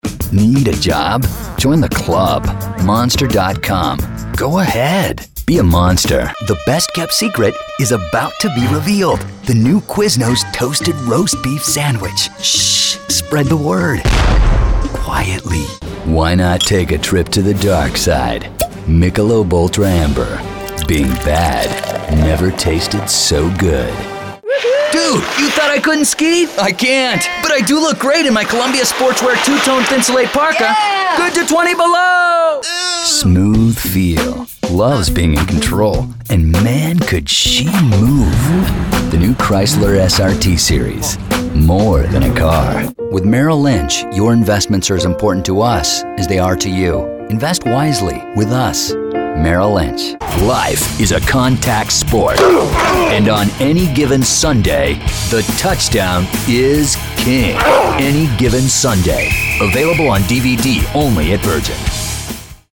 Top Bilingual Voice Over Talent in English and Spanish that has recorded hundreds of commercials, promos, narrations, corporate videos, and other project for Fortune 500 companies around the globe.
Sprechprobe: Werbung (Muttersprache):